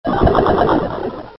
Button Sounds
Here is a collection of original button sounds for multimedia presentations.